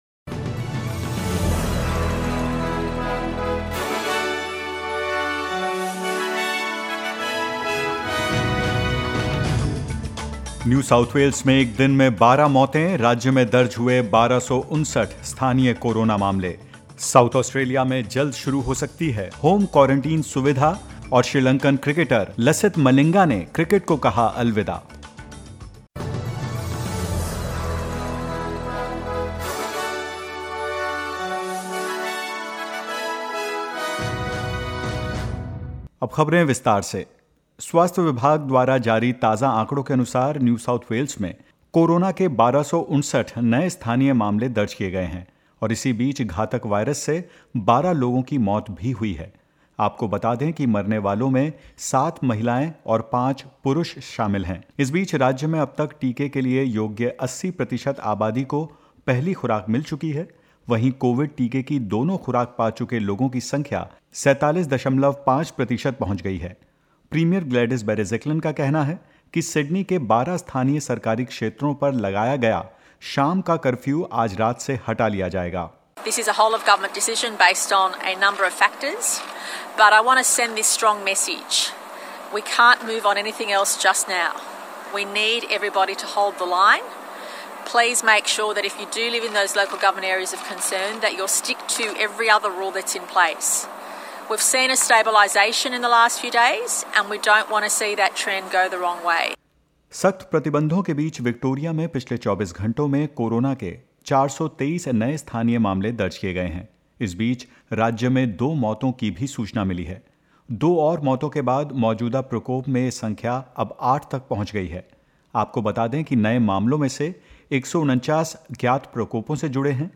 In this latest SBS Hindi News bulletin of Australia and India: 47.5 percent of the population aged over 16 gets fully vaccinated in NSW; No new community cases in Queensland and more.